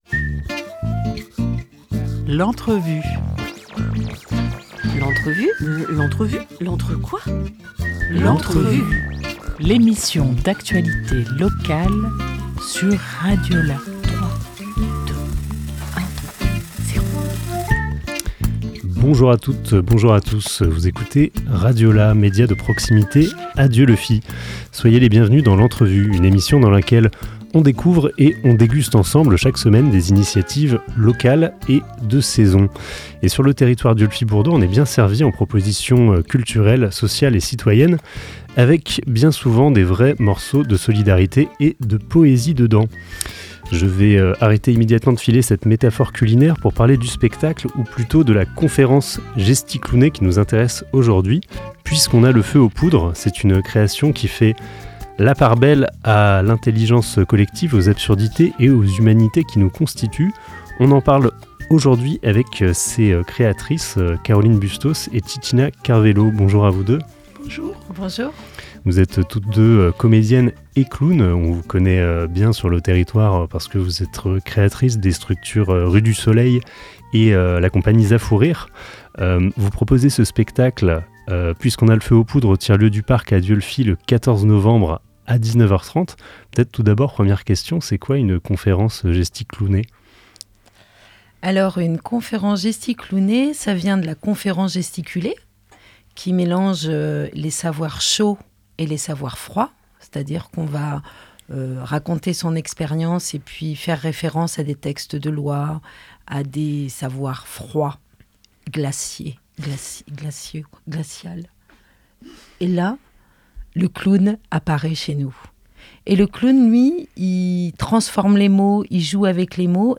7 novembre 2024 12:14 | Interview